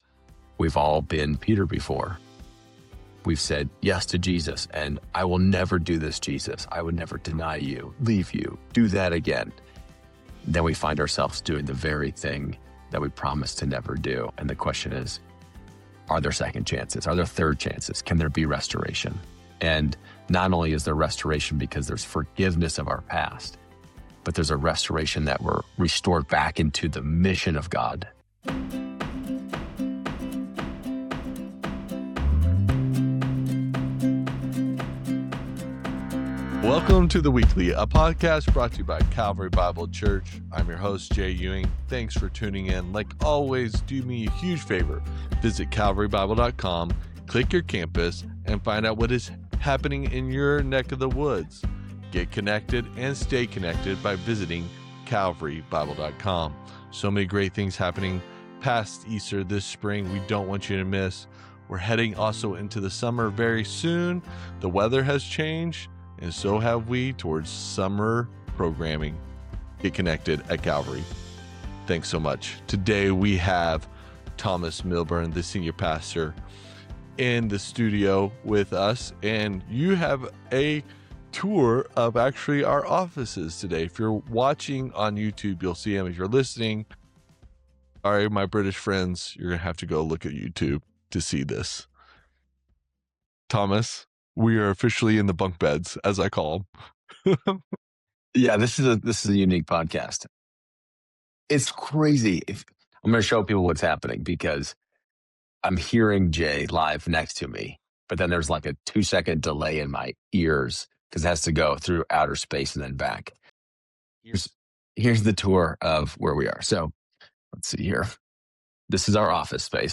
We hope this daily practice helps prepare your hearts as we celebrate Jesus’ first coming and look forward to his second.